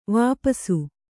♪ vāpasu